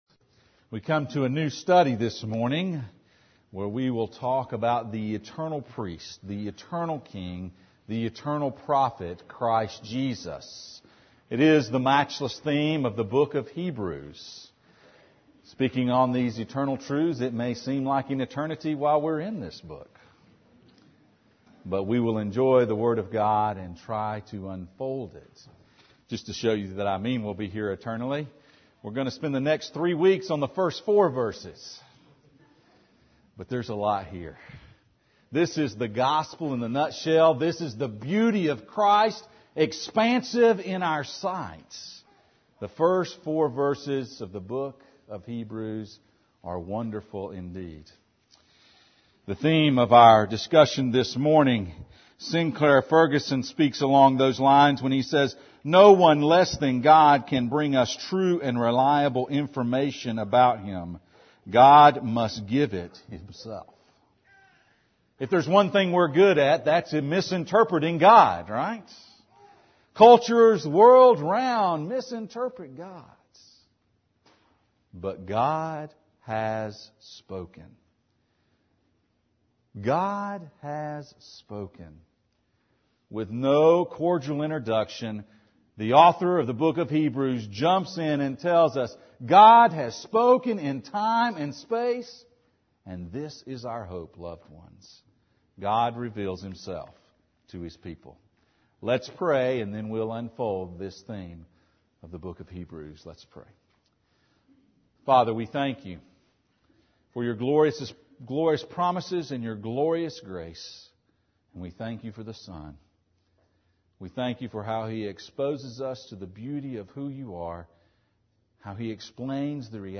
Hebrews 1:1-2 Service Type: Sunday Morning « Which Commission Are We Committed To?